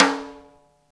rim snare p.wav